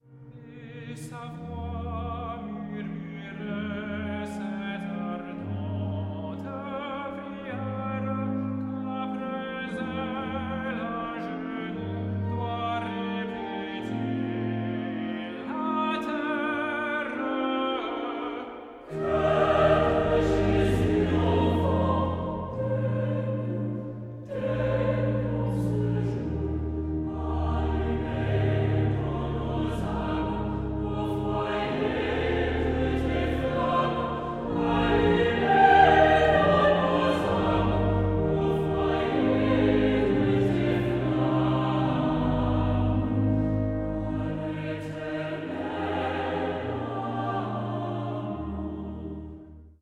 24-bit stereo
tenor
organ
Recorded 4-6 April 2018 in Exeter College Chapel, Oxford, UK